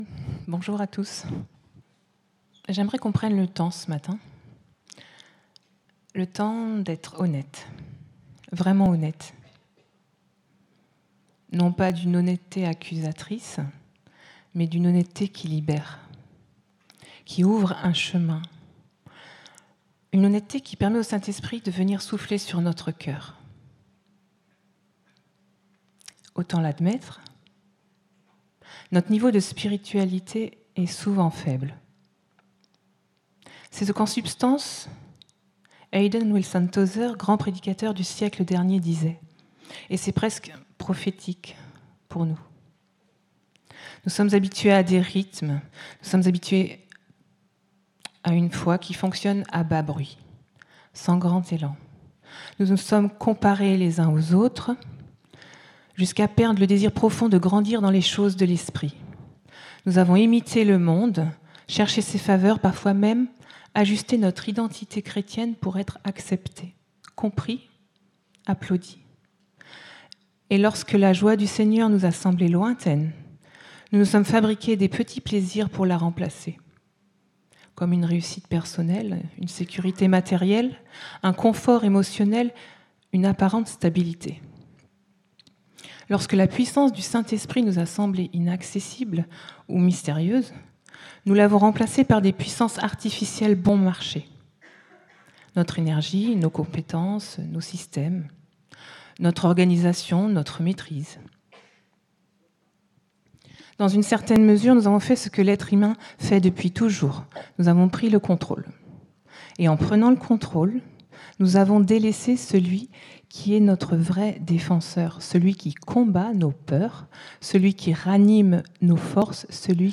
Culte-du-07-12.mp3